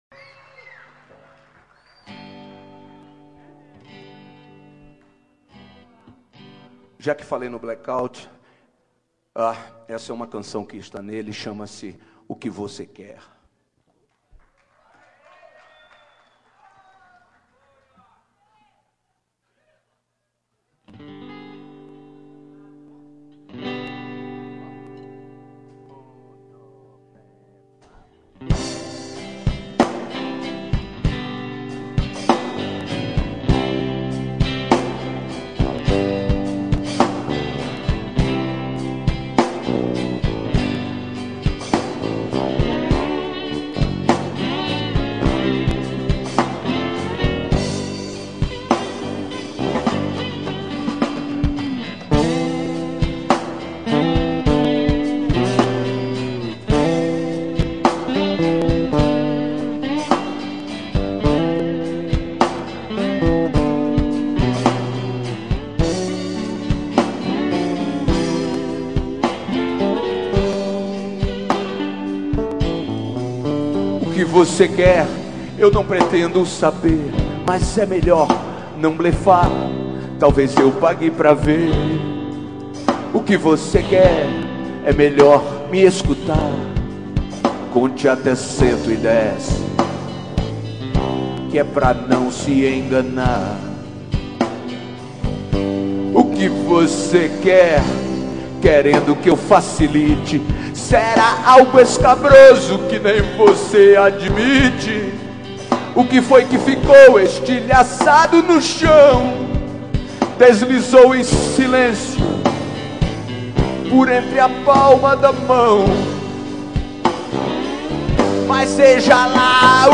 ao vivo
baixo